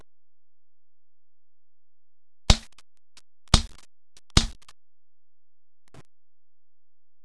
スライド固定式のガスガンですから、サイレンサーの効果が実感できます。
サイレンサー無し発射音